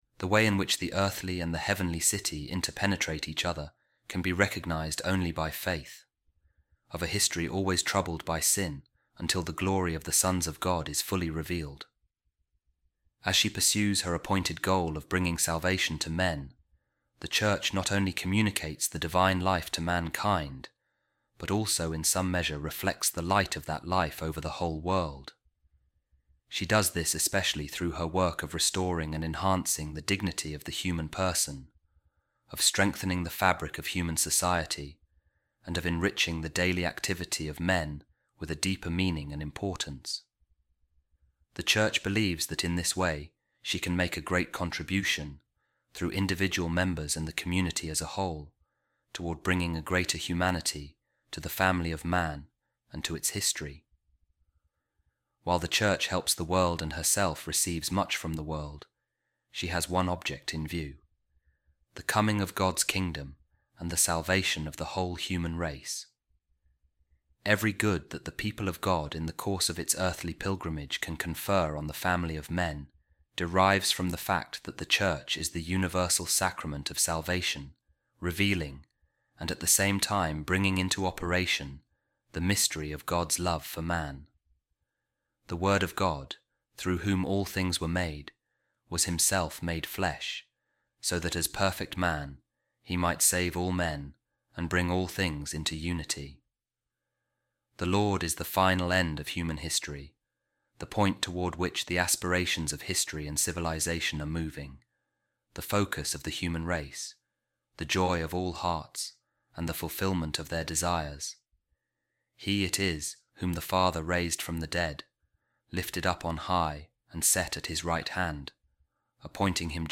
This reading reflects the vision of the Second Vatican Council concerning the Church’s relationship with the modern world. It presents human history as the meeting place of divine purpose and human freedom.